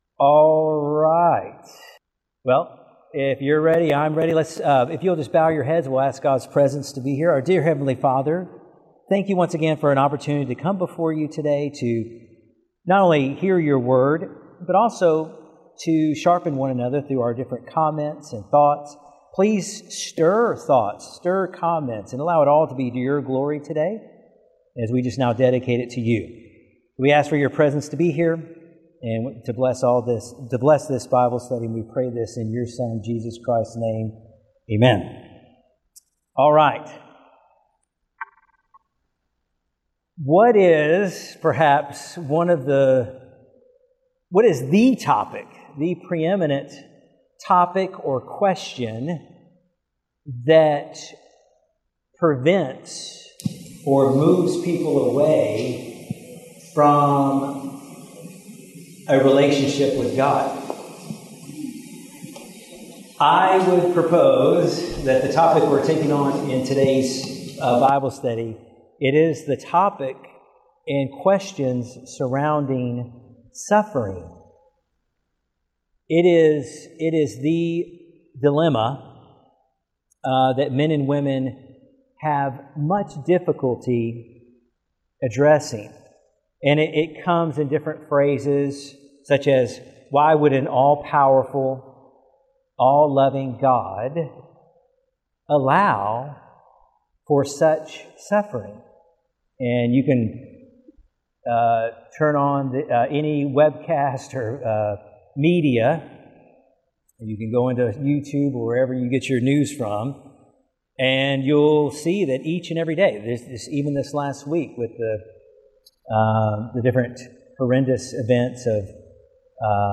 Bible Study: Why Does God Allow Suffering?